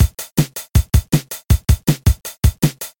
「Linn LM-1 Drum Computer」を音をエミュレートしたドラムマシンプラグインです。
・ROM 1 LM-1 に近い
■ ROM1で「MininnDrum」を再生したパターン